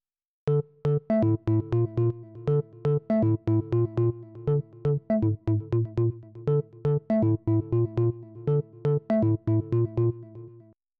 Organ Bass
sample - factory > single cycle waves > square C4:
also have some master distortion and comp